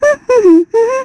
Estelle-Vox_Hum_kr.wav